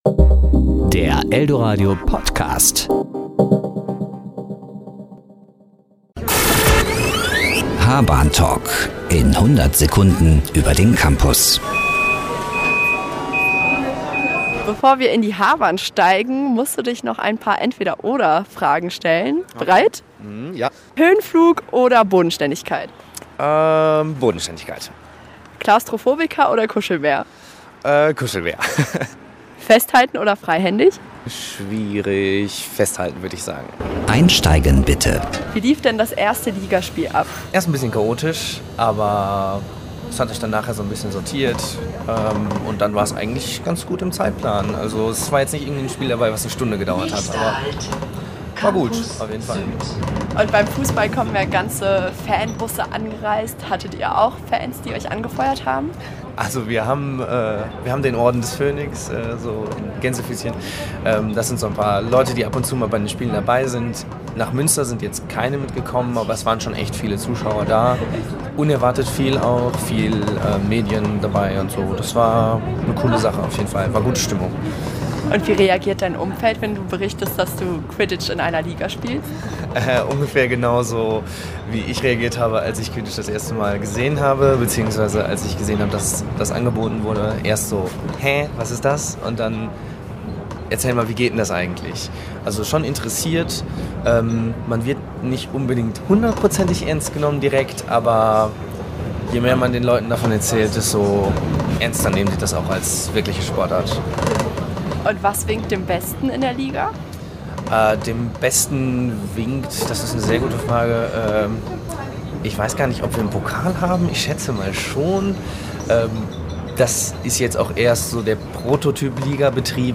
H-Bahn-Talk